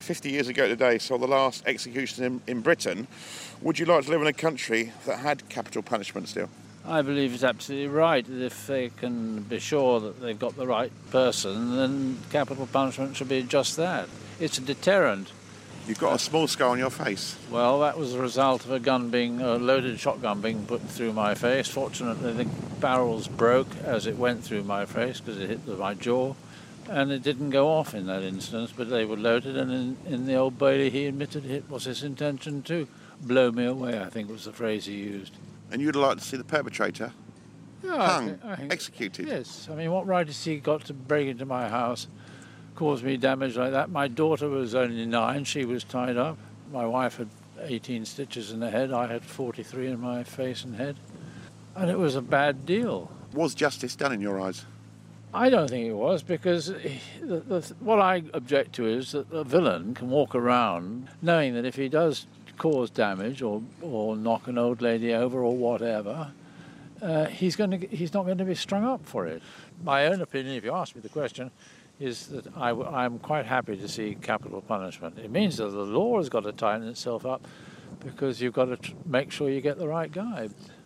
spoke to one man who was in favour of the punishment after first hand experience of serious crime.